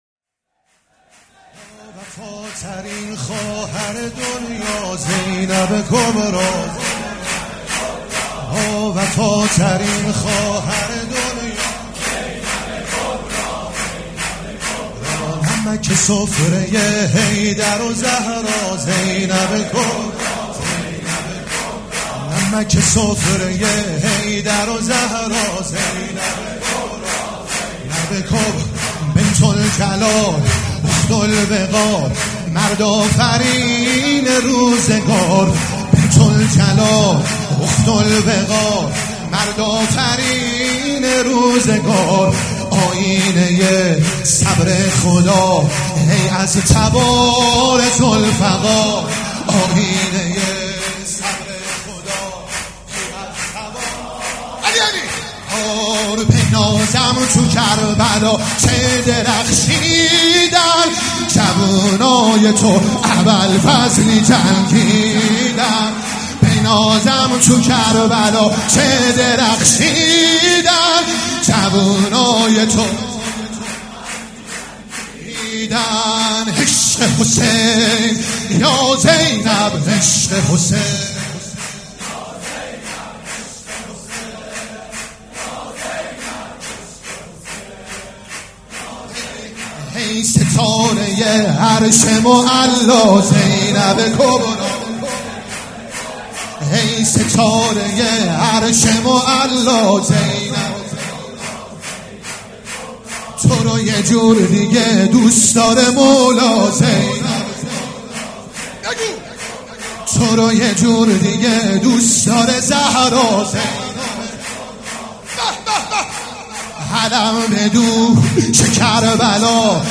سبک اثــر شور